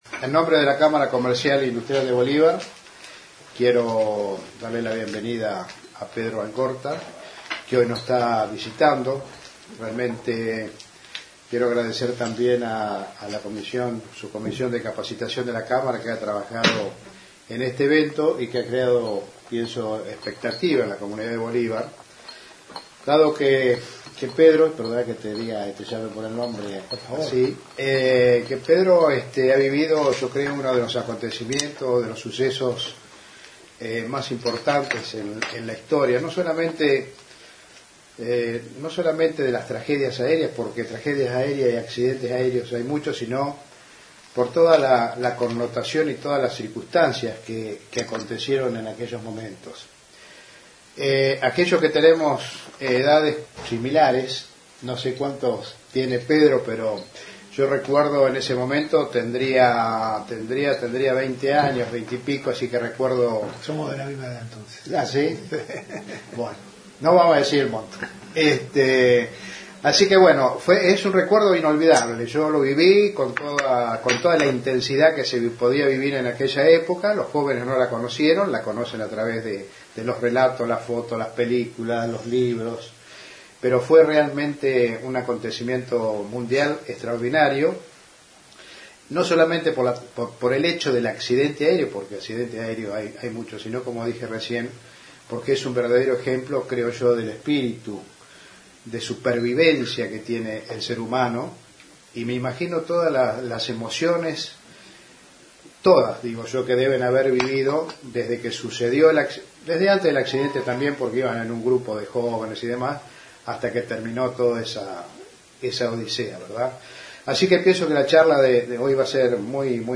Conferencia de prensa previa a su charla en la Cámara Comercial de Bolíva